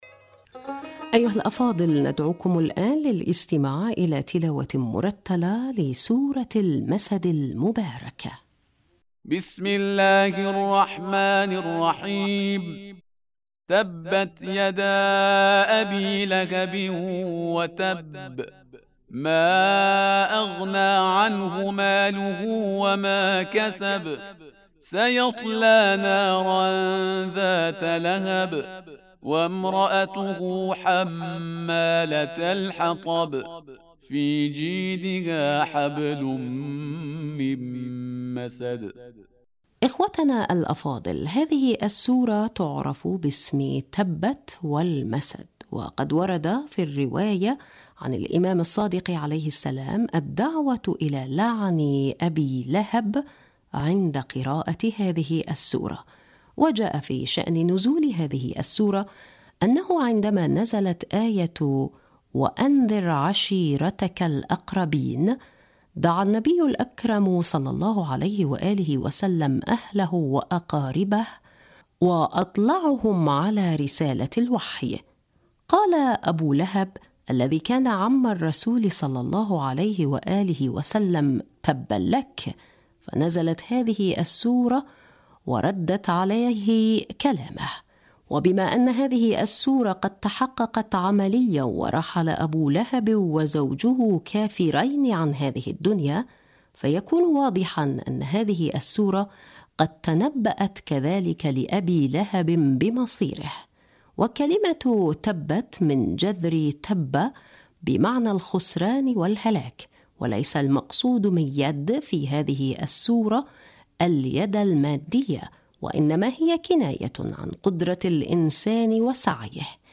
تفسير موجز لسورة المسد أیها الأفاضل، ندعوكم الآن، للإستماع الى تلاوة مرتلة لسورة المسد المباركة..